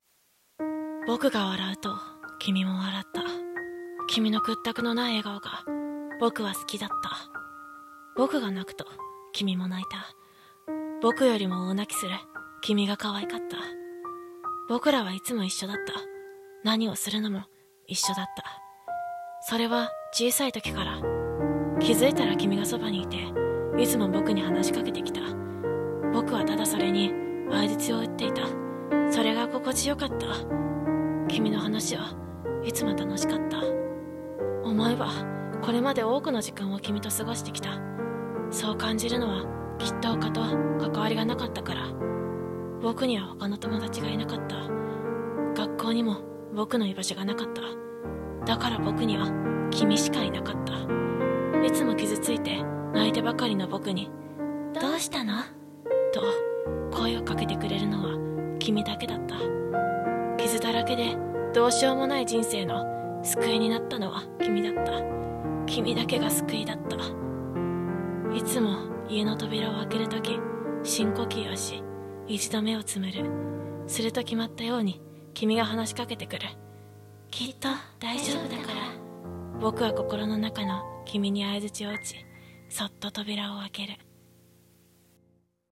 声劇「僕と君」